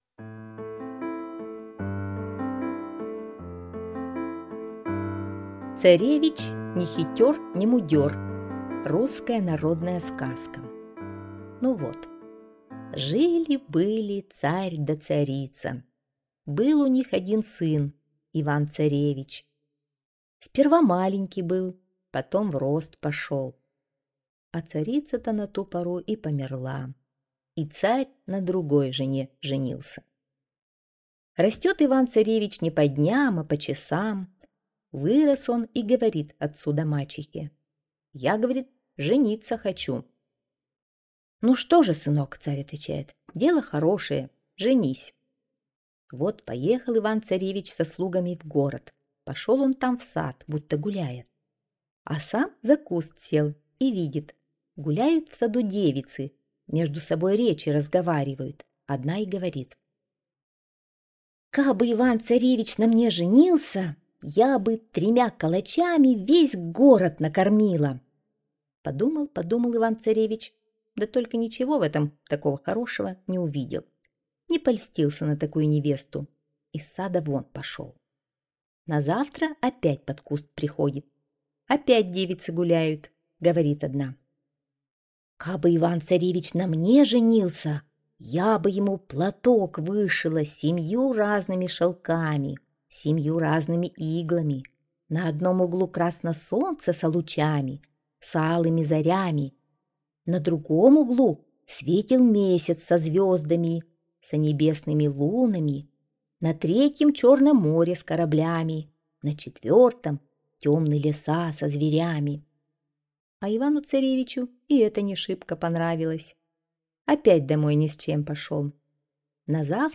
Царевич Нехитёр-Немудёр - народная аудиосказка - слушать онлайн